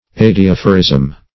Search Result for " adiaphorism" : The Collaborative International Dictionary of English v.0.48: Adiaphorism \Ad`i*aph"o*rism\ ([a^]d`[i^]*[a^]f"[-o]*r[i^]z'm), n. Religious indifference.